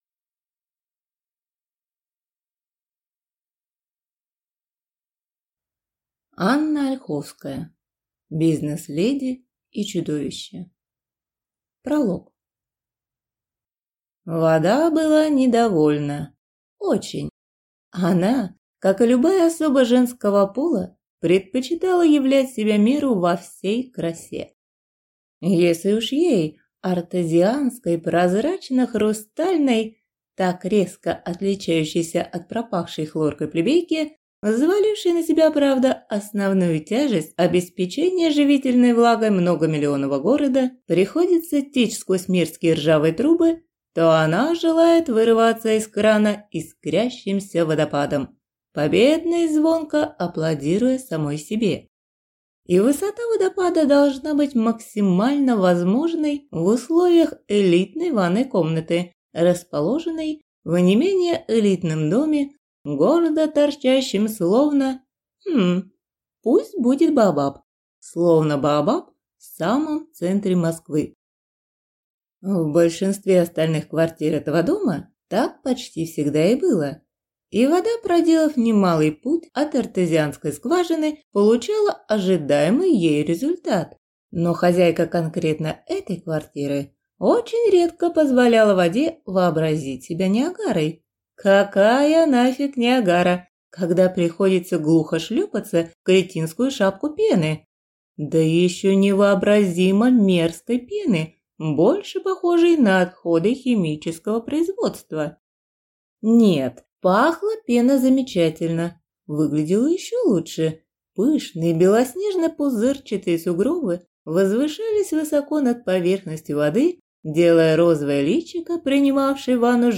Аудиокнига Бизнес-леди и чудовище | Библиотека аудиокниг